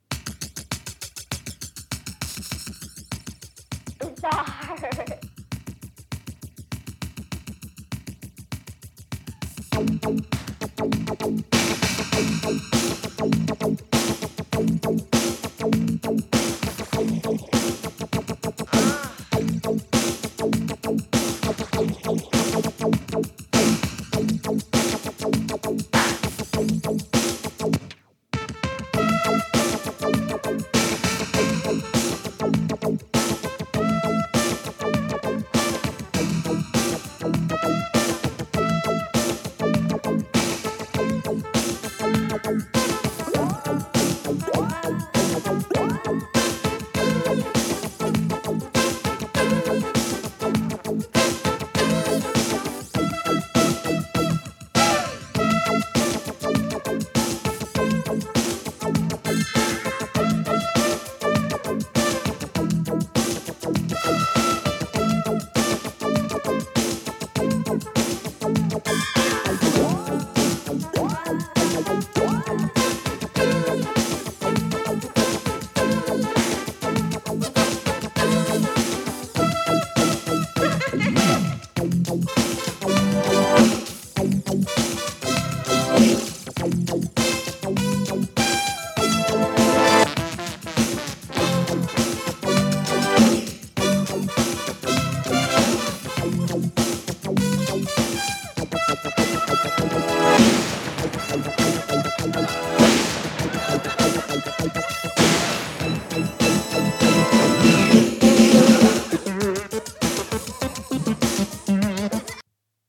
シンセ・ベースとポップなサビ・メロが印象的なエレクトロ・フュージョン・ファンク！